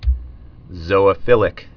(zōə-fĭlĭk)